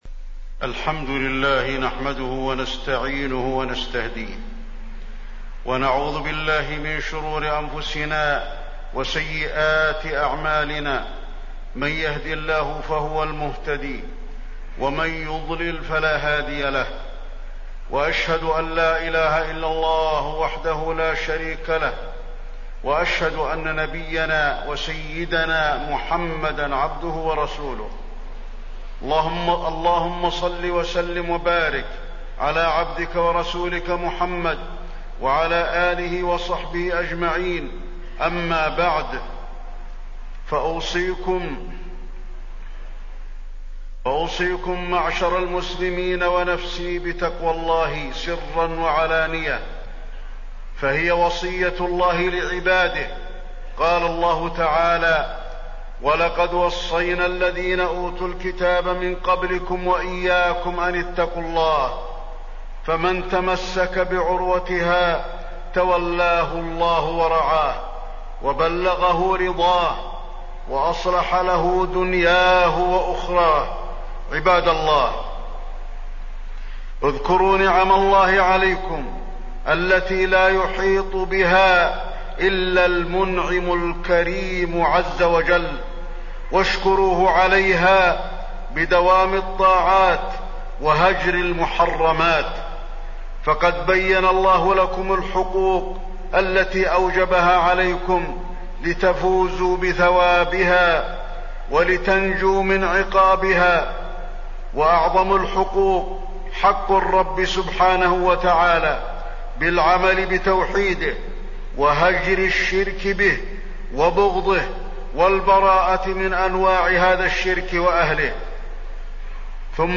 تاريخ النشر ٢ شعبان ١٤٣٠ هـ المكان: المسجد النبوي الشيخ: فضيلة الشيخ د. علي بن عبدالرحمن الحذيفي فضيلة الشيخ د. علي بن عبدالرحمن الحذيفي بر الوالدين